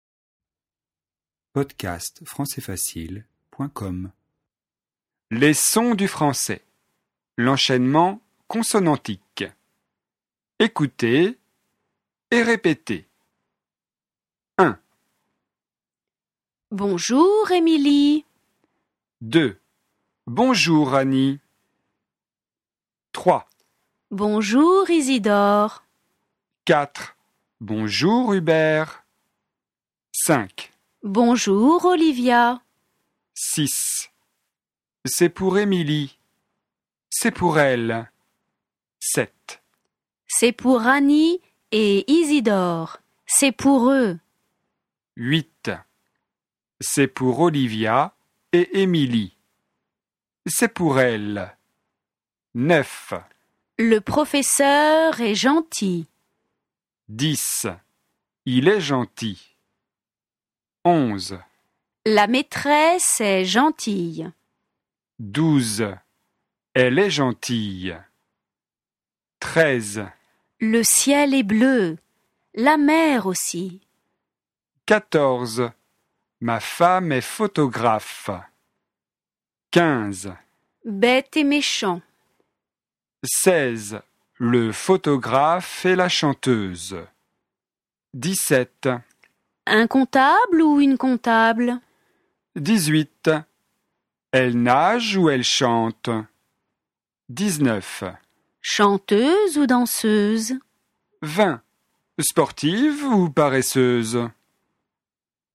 L'enchaînement consonantique, c'est lorsqu'on prononce la consonne finale d'un mot avec la voyelle qui débute le mot suivant.
On ne fait pas de pause entre les deux mots.